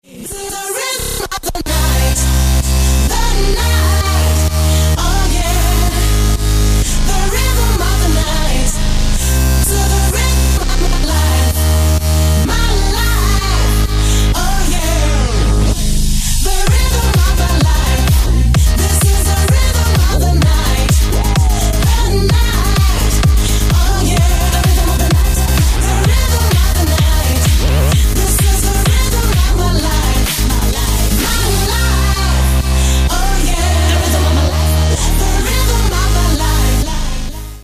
• Качество: 128, Stereo
громкие
женский вокал
dance
Electronic
EDM
электронная музыка
club
electro house